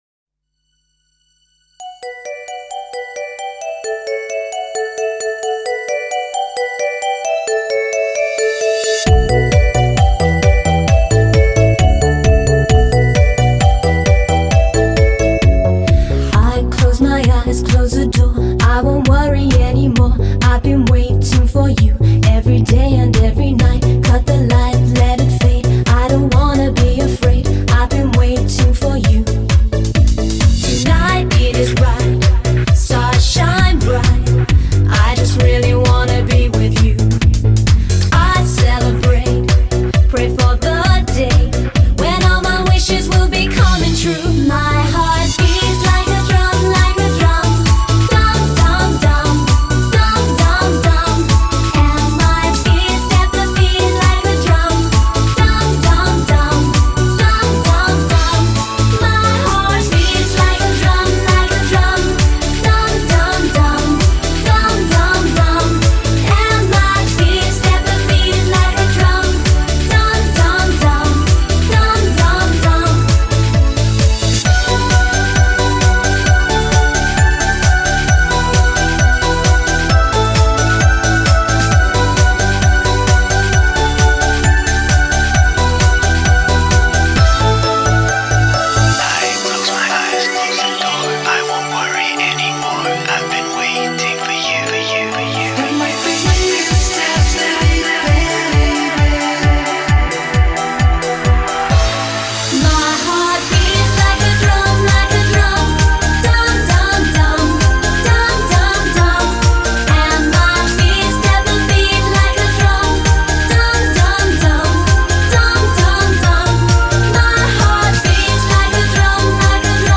BPM132-132
Audio QualityPerfect (High Quality)